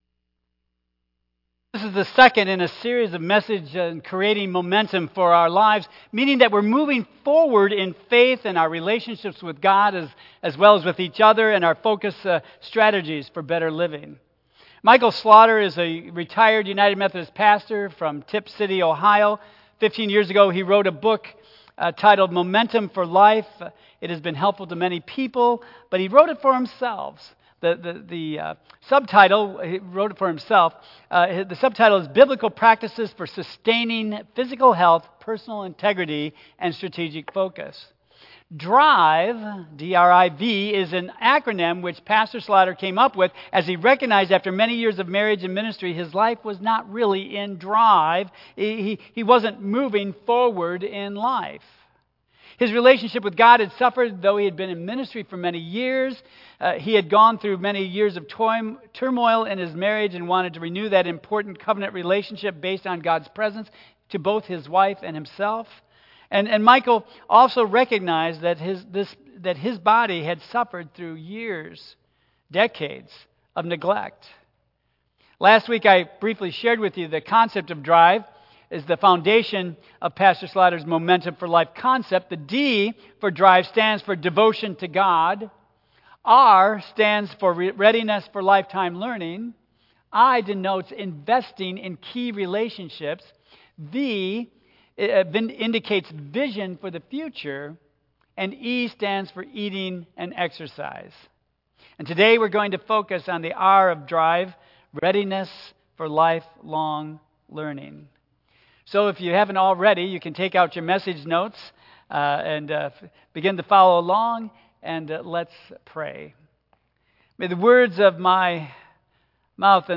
Tagged with Michigan , Sermon , Waterford Central United Methodist Church , Worship Audio (MP3) 10 MB Previous Put Your Life in DRIVE Next I Must See Jesus